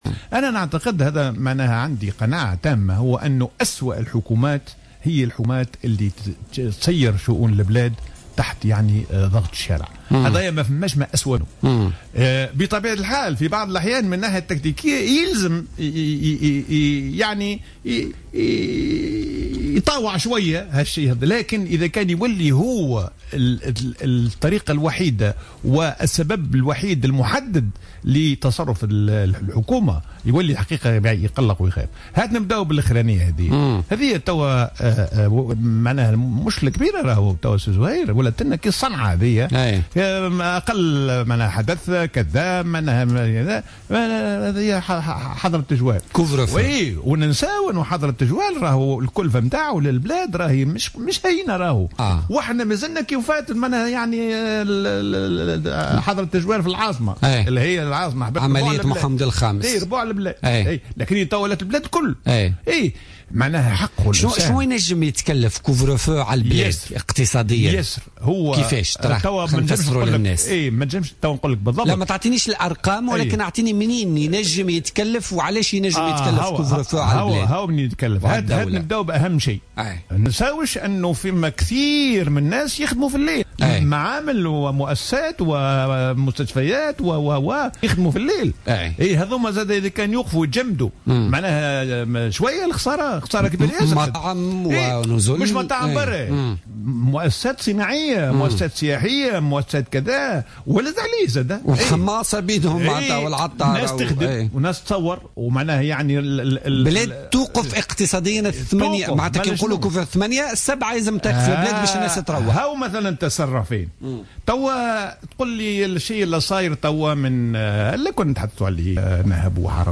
اعتبر حسين الديماسي الخبير الاقتصادي ووزير المالية السابق ، ضيف برنامج بوليتكا لليوم الجمعة 22 جانفي 2016 أن حظر التجول هو قرار متسرع من حيث طريقة اتخاذه.